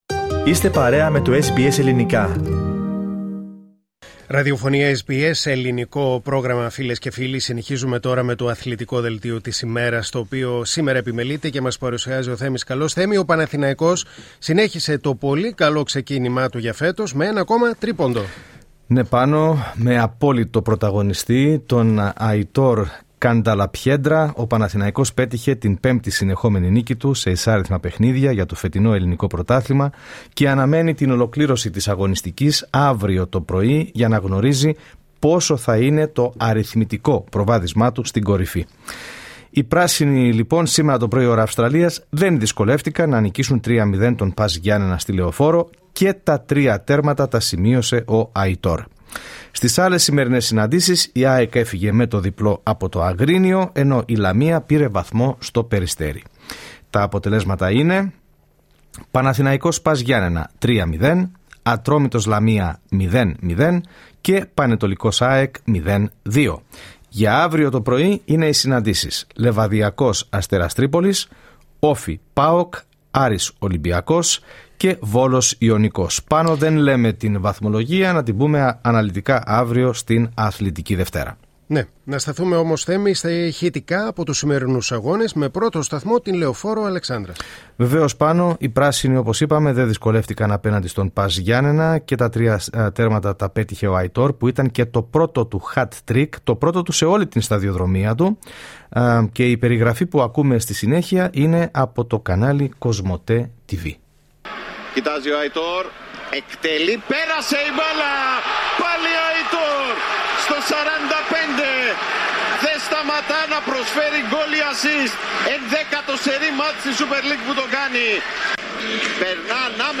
Ακούμε την περιγραφή από το κανάλι Cytavision: